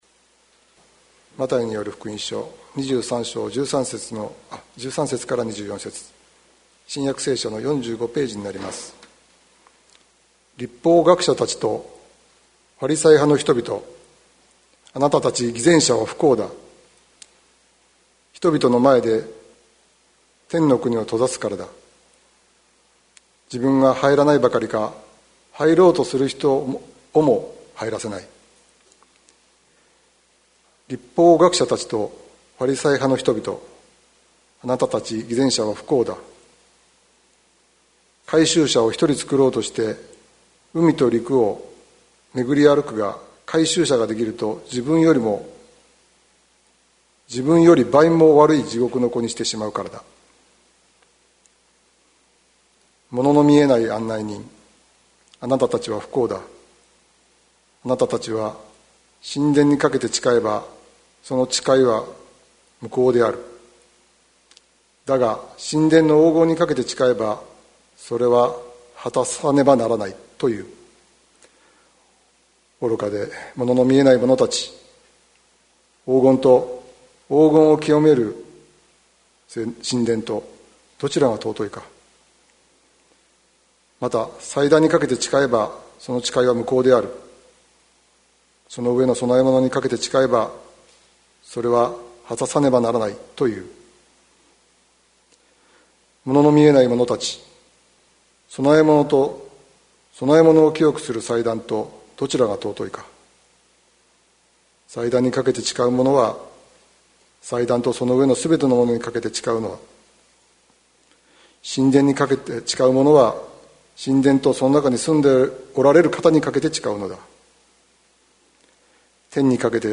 2020年11月01日朝の礼拝「偽善からの解放」関キリスト教会
説教アーカイブ。